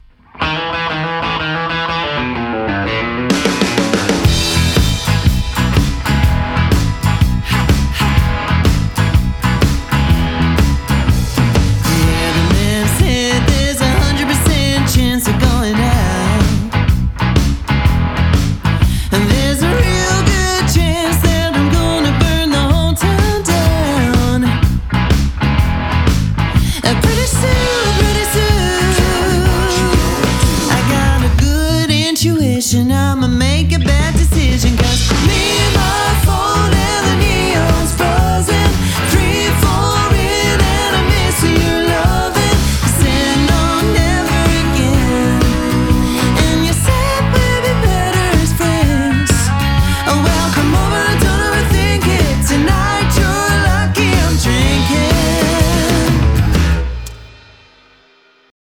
Nashville Energy Meets Party Anthems – UK Style